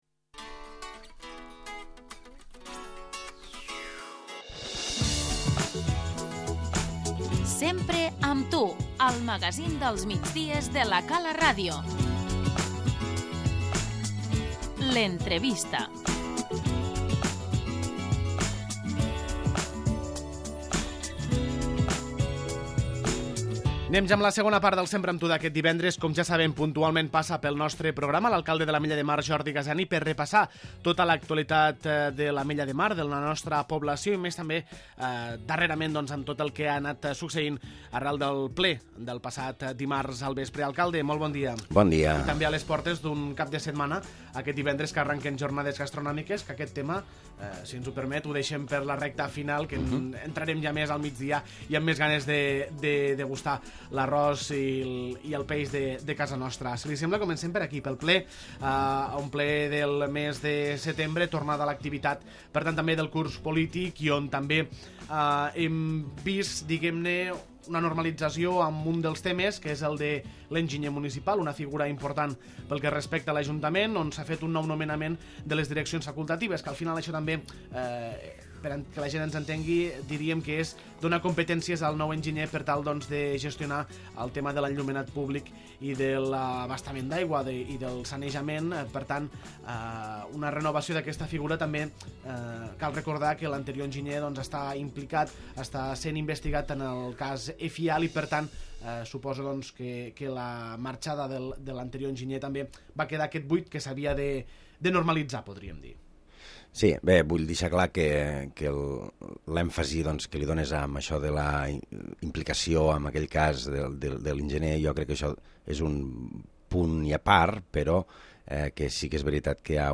L'entrevista - Jordi Gaseni, alcalde